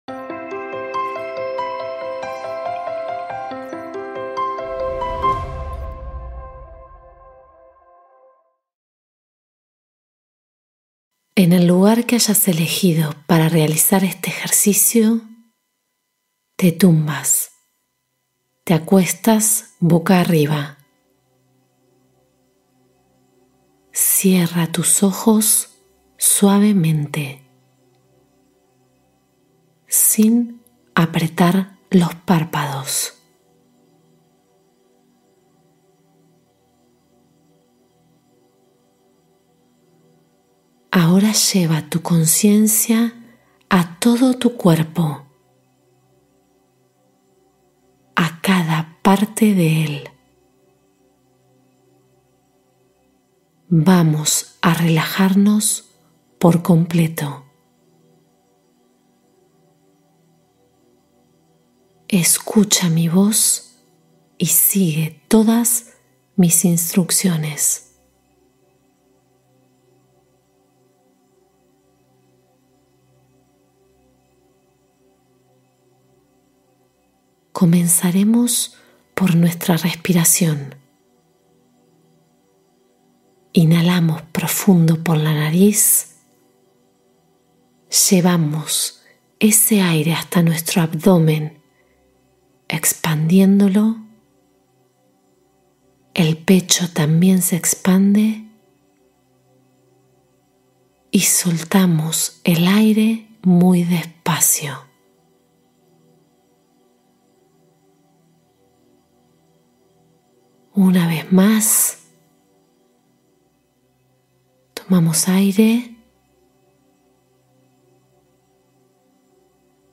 Meditación guiada de 15 minutos para dormir profundamente y restaurar tu cuerpo